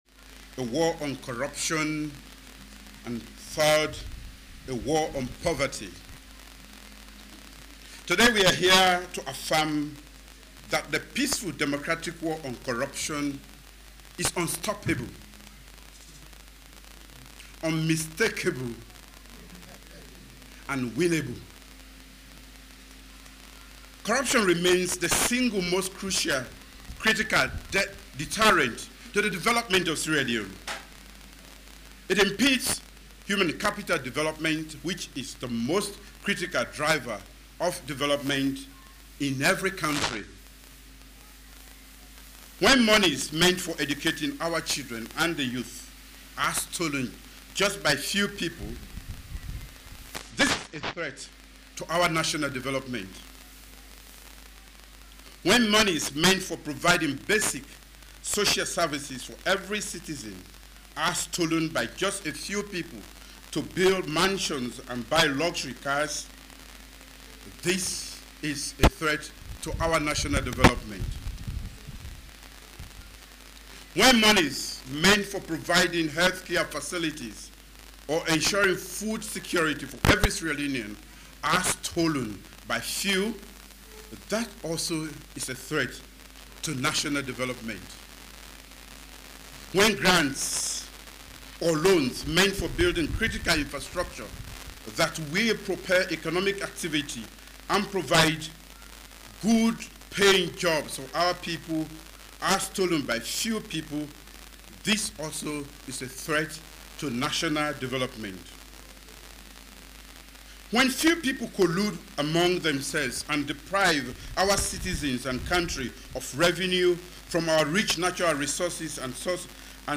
Justices Biobele Georgewill – from Nigeria, Bankole Thompson – a Sierra Leonean Judge, and William Annan Atuguba – from Ghana, took their oath of office this morning at State House in Freetown.
president-Bio-speaks-at-swearing-in-of-commissions-of-inquiry-judges.m4a